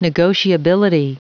Prononciation du mot negotiability en anglais (fichier audio)
Prononciation du mot : negotiability